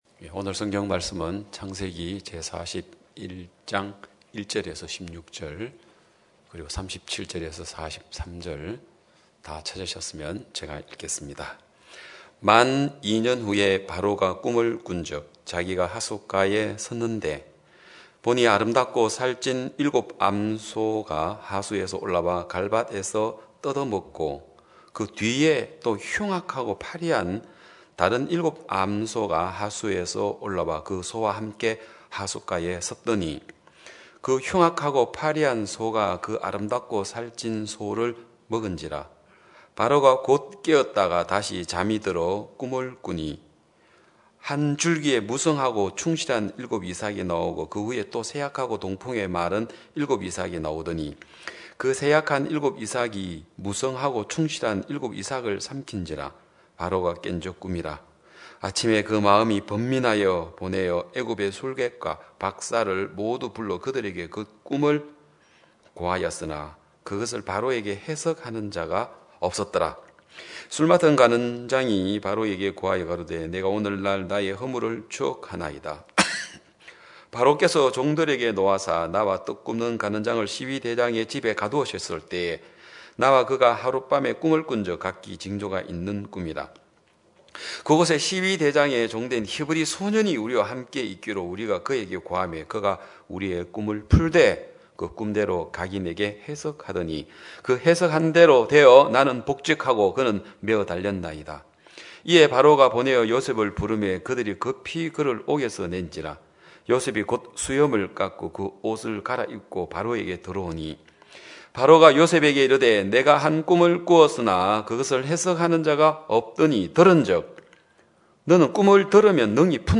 2021년 12월 12일 기쁜소식양천교회 주일오전예배
성도들이 모두 교회에 모여 말씀을 듣는 주일 예배의 설교는, 한 주간 우리 마음을 채웠던 생각을 내려두고 하나님의 말씀으로 가득 채우는 시간입니다.